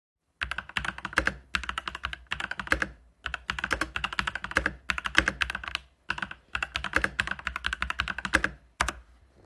Visgi K70 CORE yra kitokia už daugelį kitų MX Red jungiklius naudojančių klaviatūrų dėl užtylinto korpuso. Išgirdus klavišus iškart kyla įtarimas apie galimai panaudotus MX Silent Red jungiklius – tok triukšmą sugeriančiomis medžiagomis išklotas K70 CORE korpusas bei papildomas jungiklių sutepimas.